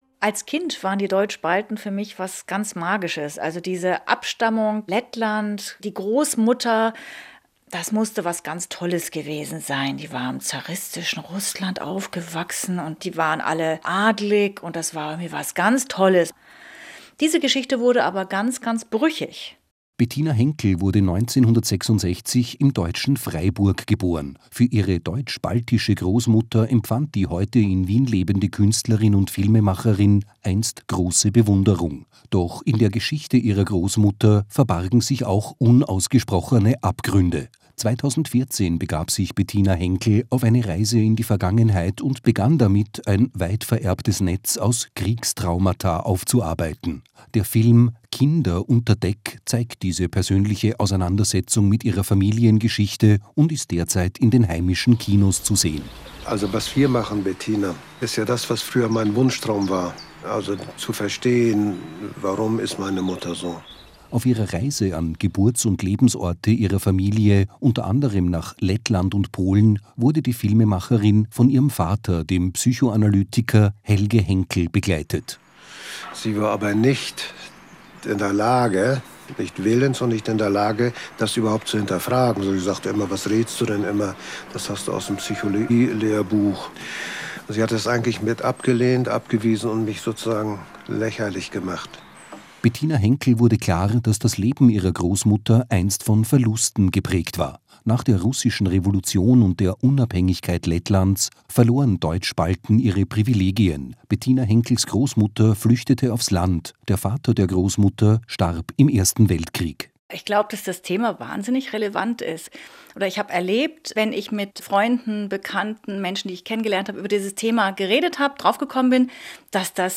Radiobeitrag, 16.5.2019